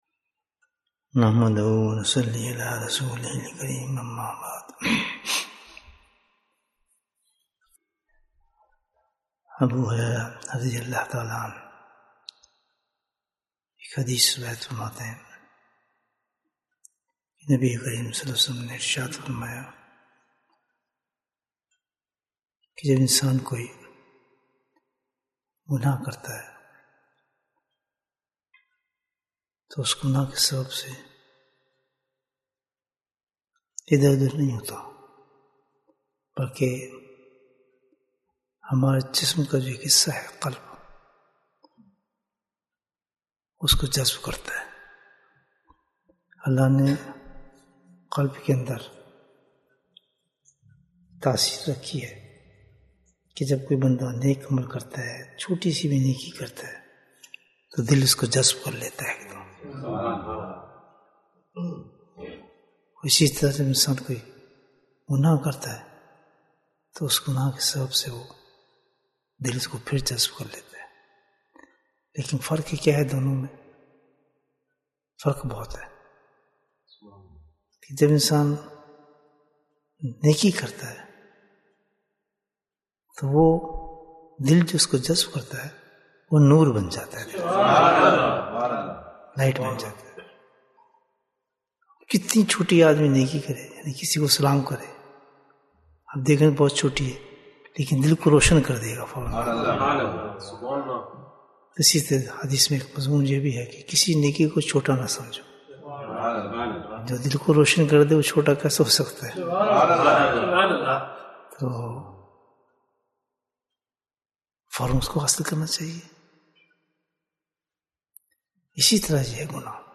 گناہ سے بچو Bayan, 15 minutes14th May, 2023